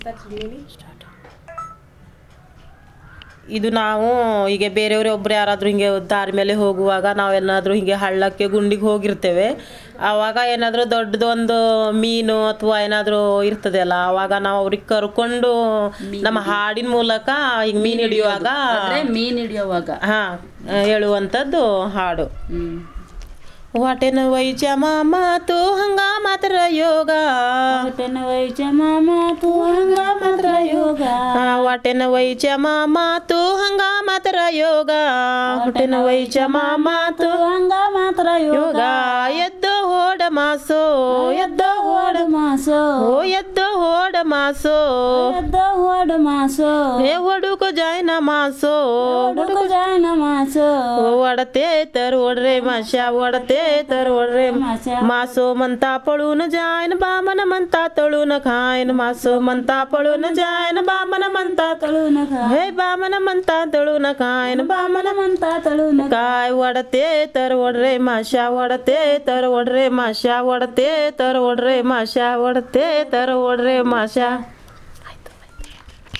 Performance of traditional folk 'Watenu waita' song
traditional folk song
usually sung while fishing.